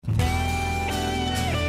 The Tribute Memorial Song Dedicated to all who
Guitar
Drums
Background vocals
Organ
Rythym Guitar, Lead Vocals, Bass Guitar, Strings